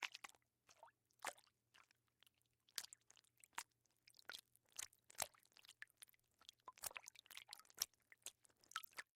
Звук пельменя обмакивают в сметане или соусе